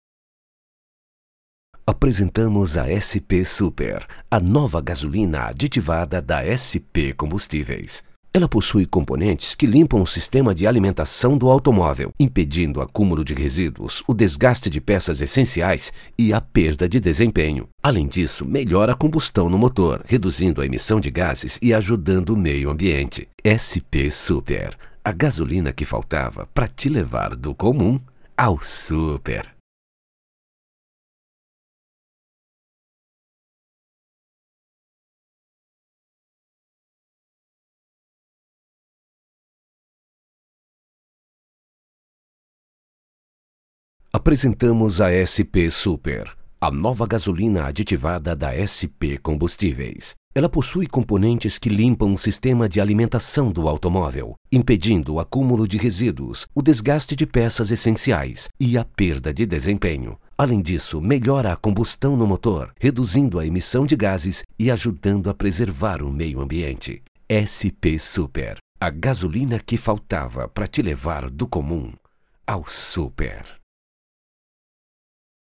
offering a confident, versatile baritone voice
Automotive
Neumann tlm103, Audio Technica AT 4033, Avalon vt737SP, Audient Id14, Yamaha HS50, Mac Mini M1